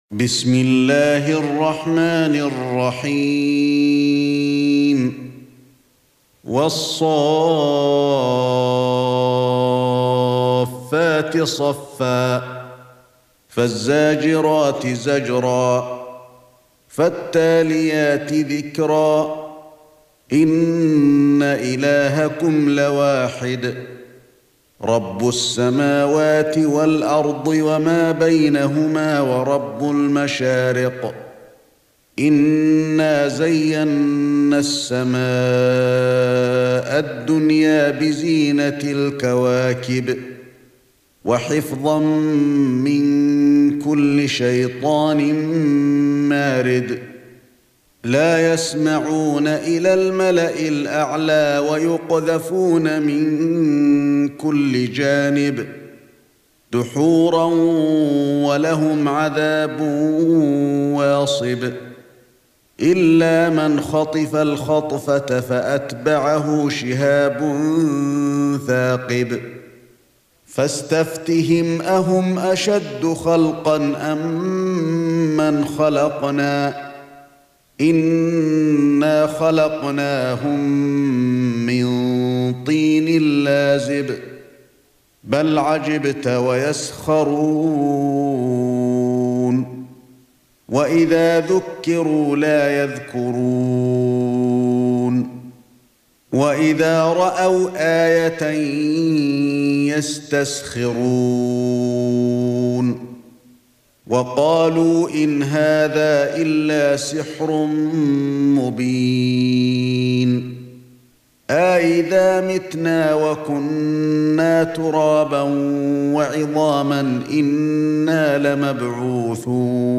سورة الصافات ( برواية قالون ) > مصحف الشيخ علي الحذيفي ( رواية قالون ) > المصحف - تلاوات الحرمين